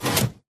piston
should be correct audio levels.